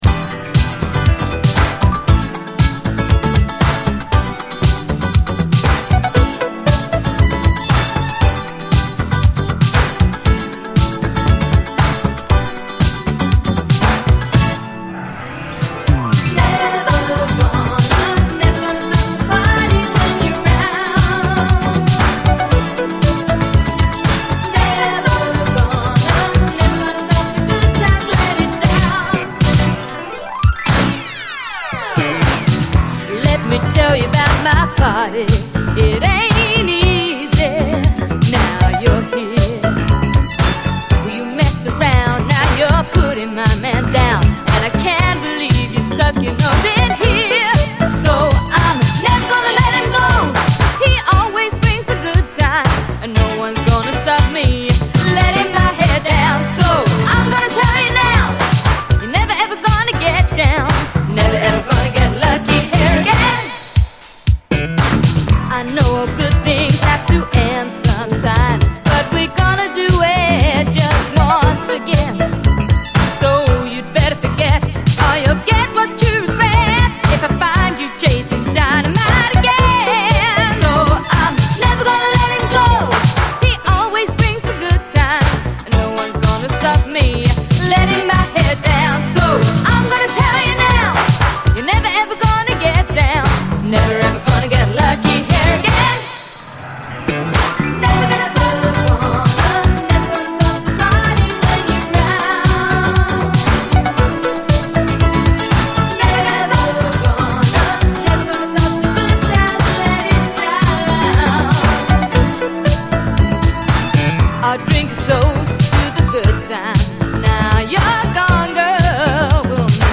SOUL, 70's～ SOUL, DISCO
キャッチー＆ビューティフルなUK産シンセ・モダン・ブギー！